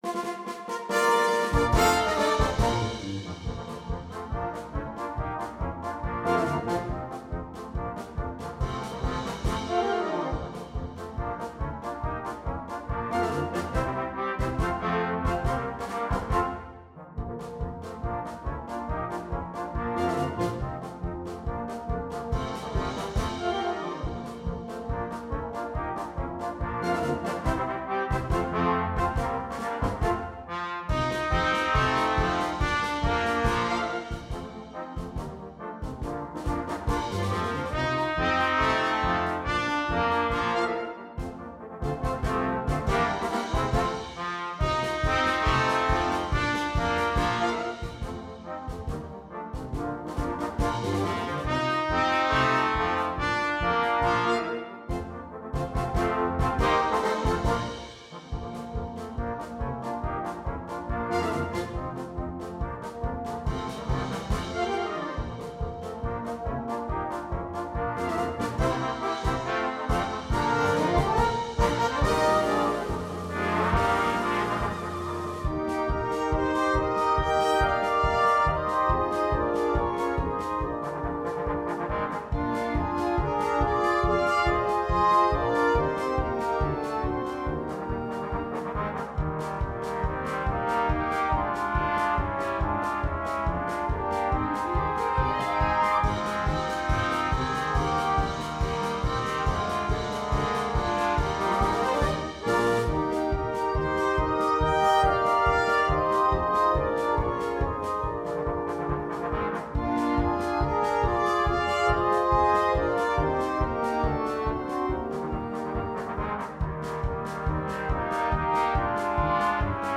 2. Egerlander-style band
Full Band
Trombone (Trio)
Entertainment